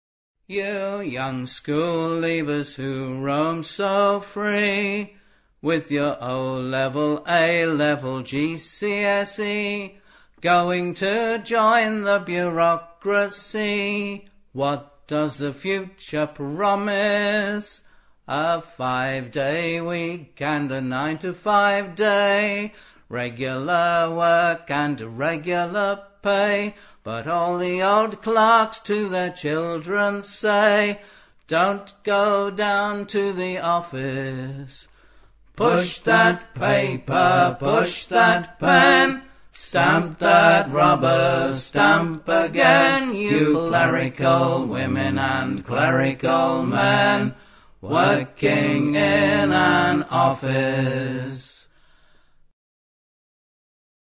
lyricist vocalist humorist guitarist